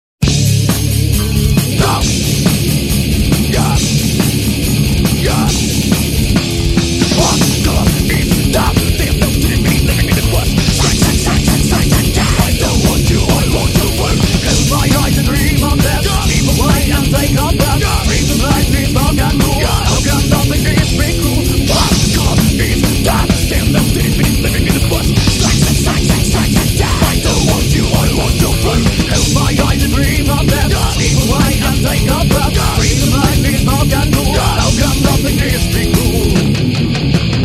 мужской голос
громкие
электрогитара
Металкор
Драйвовый рок рингтон